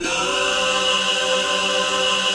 DM PAD4-11.wav